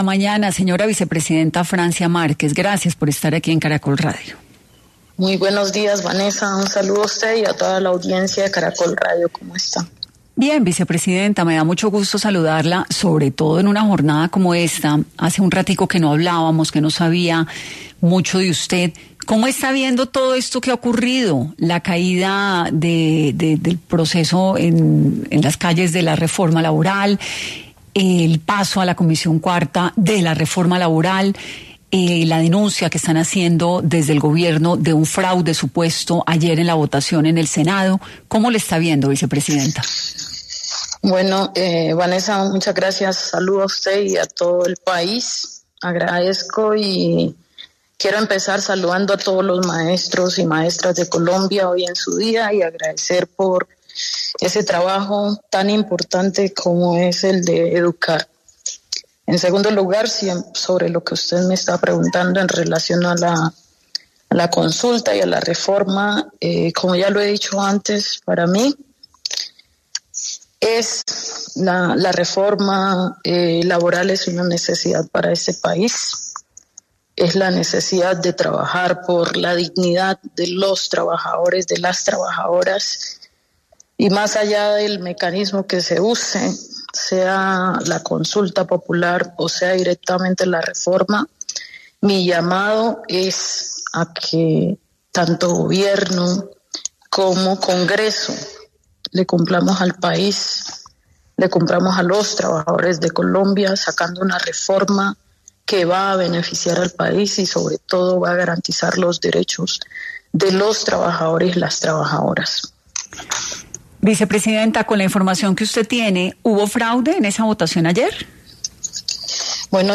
En 10AM de Caracol Radio, la vicepresidenta Francia Márquez, expresó lo que espera de la reforma laboral en el país hacia el futuro.